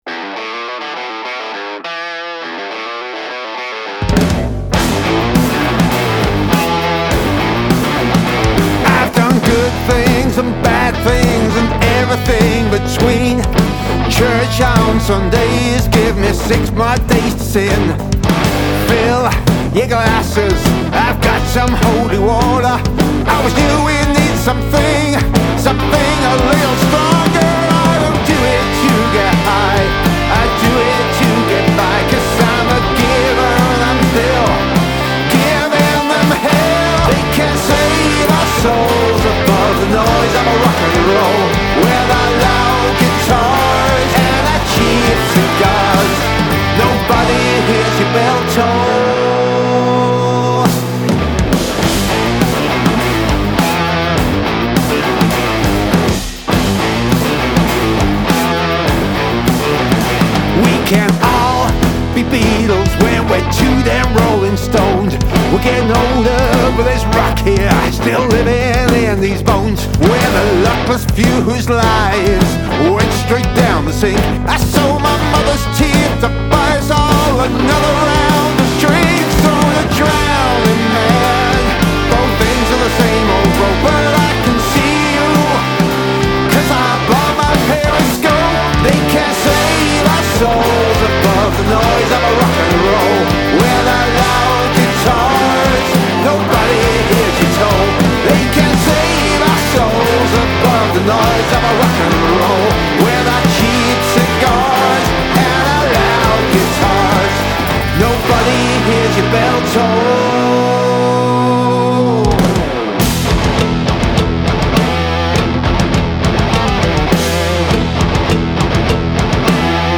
Male Vocal, Guitar, Bass Guitar, Drums